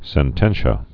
(sĕn-tĕnshə, -shē-ə)